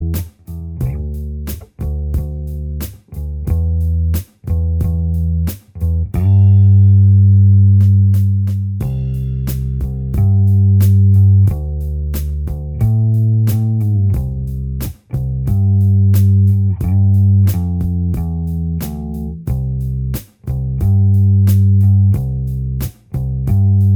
Minus Guitars Soft Rock 4:31 Buy £1.50